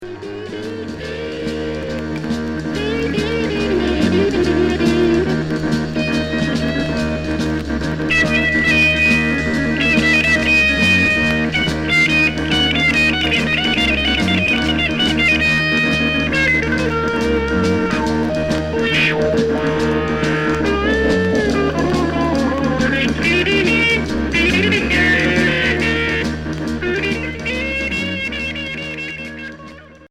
Progressif psychédélique